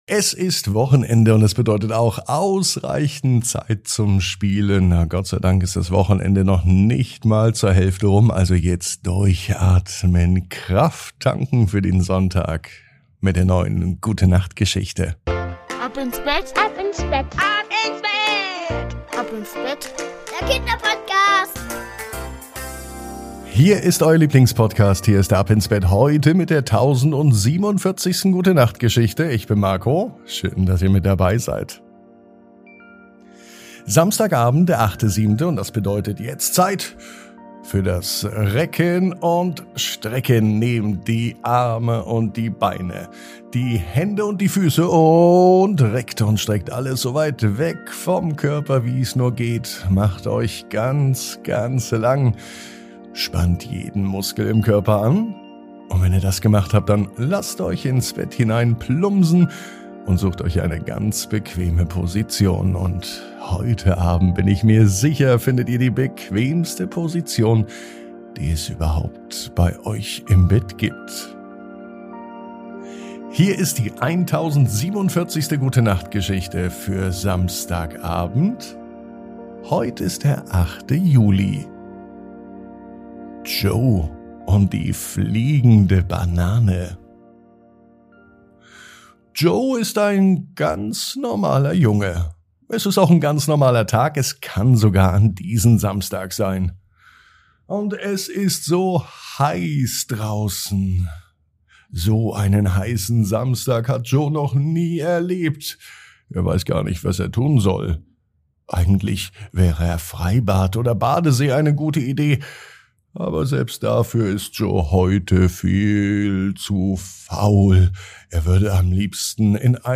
Perfekt zum Träumen und Einschlafen! Begleite Joe auf seinem außergewöhnlichen Abenteuer und lass dich von der Vorlesestimme von "Ab ins Bett" in eine Welt voller Fantasie entführen.